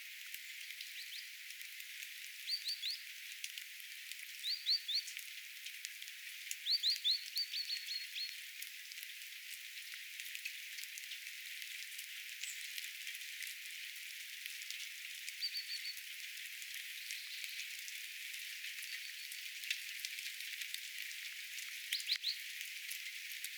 tuolla tavoin ääntelevä talitiainen
Se äänteli sointuvamminkin.
Silloin tuli mieleen, että ääni muistuttaa
ehkä hieman erästä pähkinänakkelin äänityyppiä.
tuolla_tavoin_aantelevia_talitiaislintuja_saaressa_ainakin_kaksi.mp3